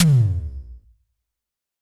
Tom_B4.wav